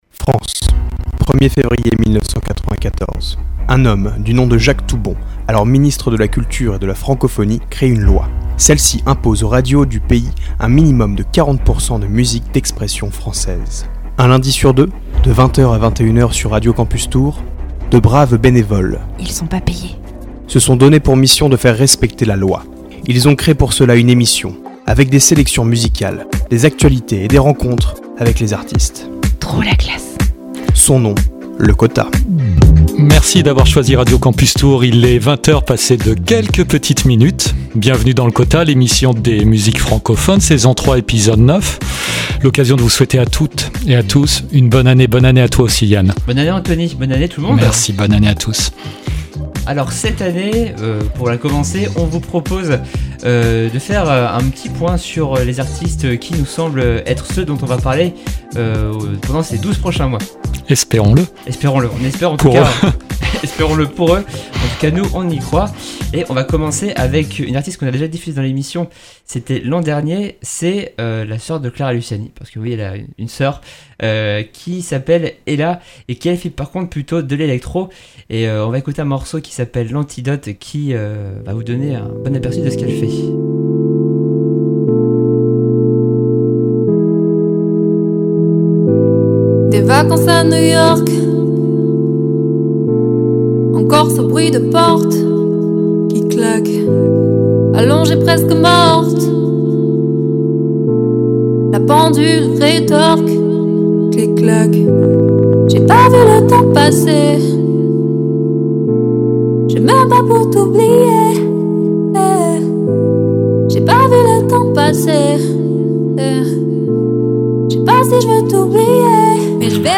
Le Quota, c’est le magazine des musiques francophones de Radio Campus Tours un lundi sur deux de 20h à 21h et en rediffusion le vendredi à 8h45.